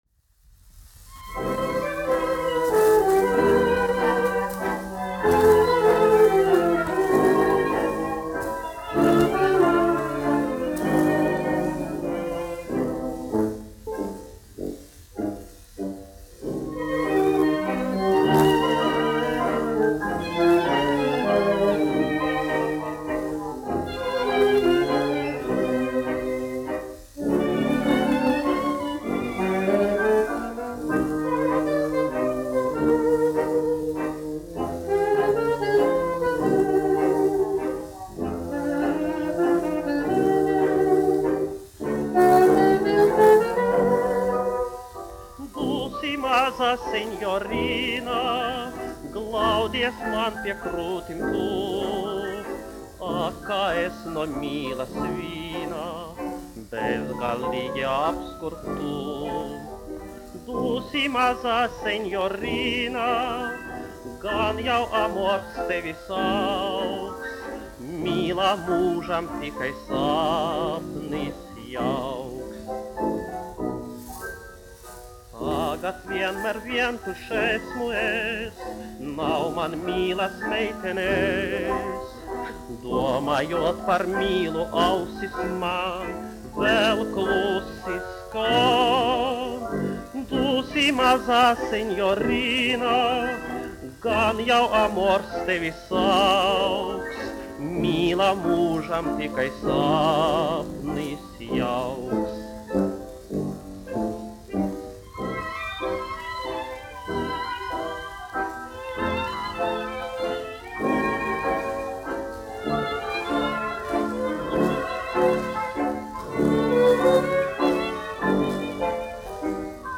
1 skpl. : analogs, 78 apgr/min, mono ; 25 cm
Populārā mūzika
Valši
Skaņuplate